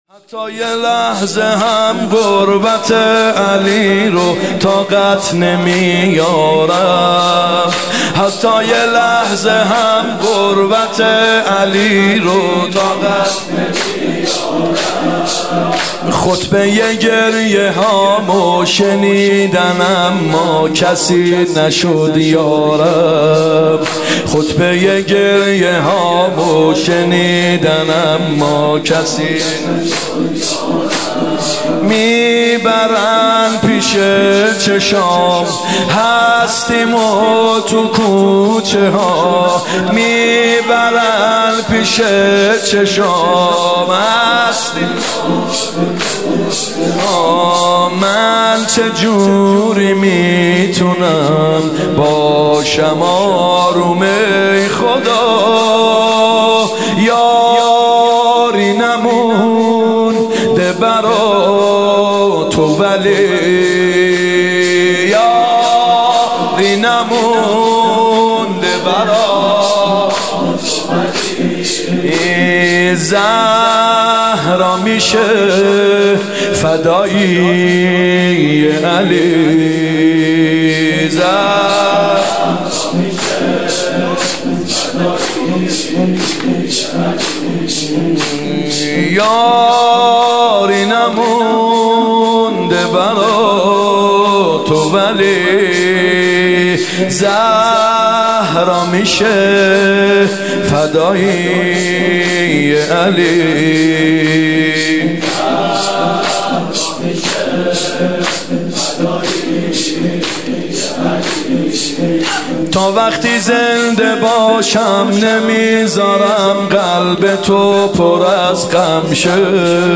فاطمیه دوم هیئت یامهدی (عج)